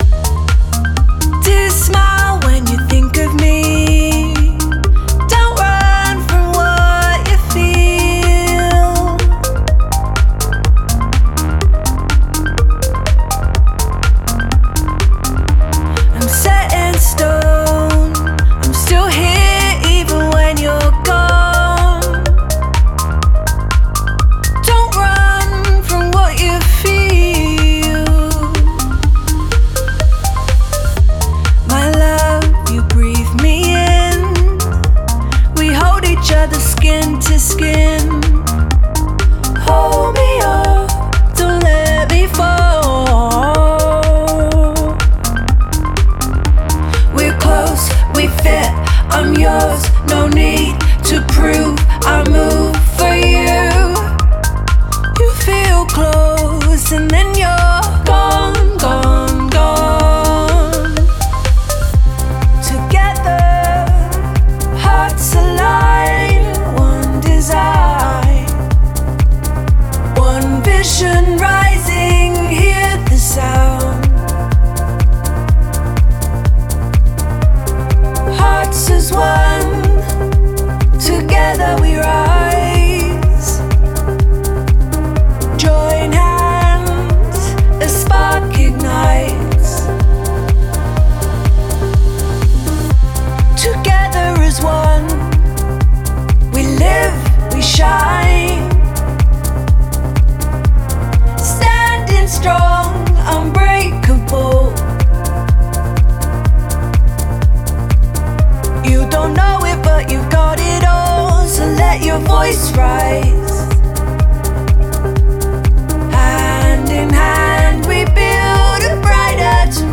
Genre:House
ボーカリストの感情豊かな表現により、歌詞のテーマである「団結」「所属感」「手を取り合うこと」が美しく伝わります。
デモサウンドはコチラ↓
37 Vocal Ensemble Loops
37 Harmony Vocal Loops
102 Doubled Vocal Loops